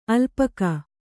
♪ alpaka